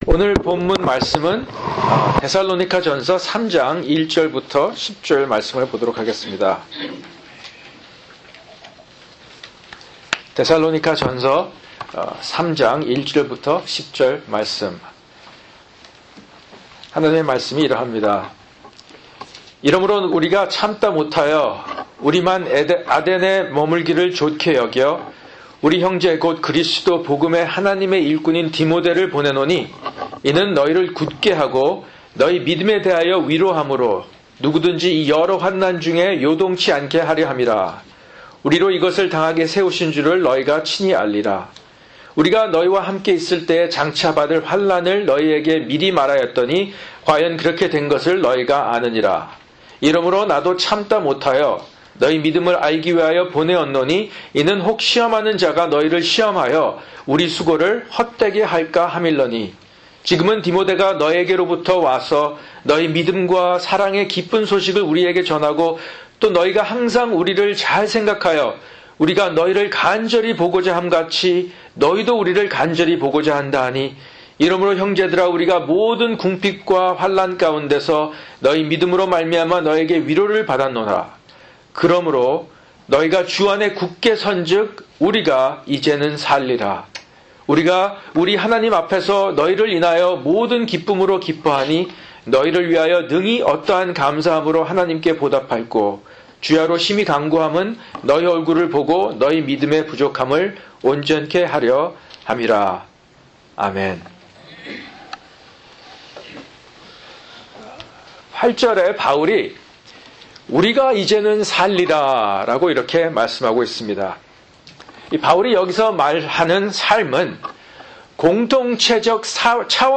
[주일 설교] 데살로니가전서 3:1-10